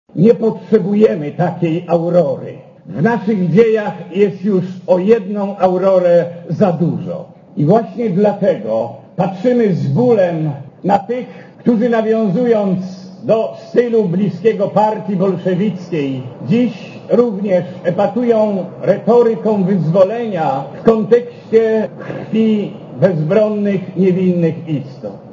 Dziś wnuczęta „Aurory” chcą przyjść do nas z nowymi propozycjami etycznymi i komercjalnymi - mówi z oburzeniem arcybiskup Życiński.
Dla Radia Zet mówi arcybiskup Życiński (98 KB)